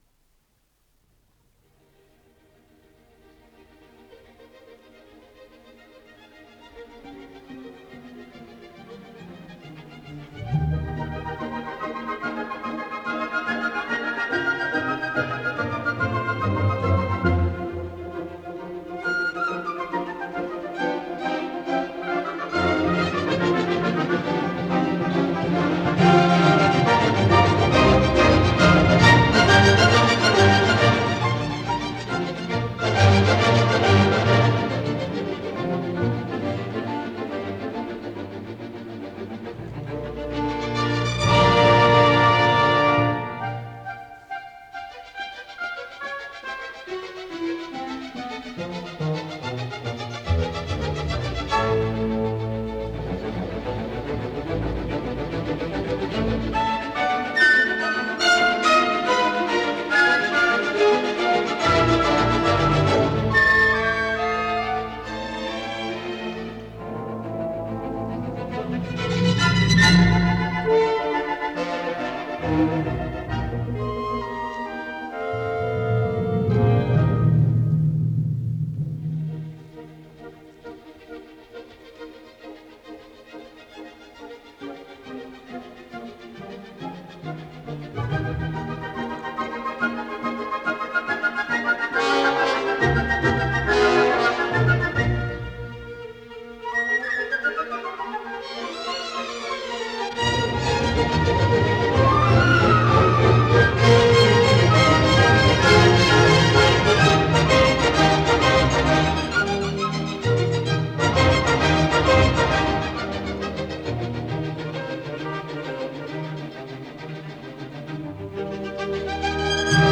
с профессиональной магнитной ленты
Скерцо престиссимо
ИсполнителиГосударственный симфонический оркестр СССР
Дирижёр - К. Иванов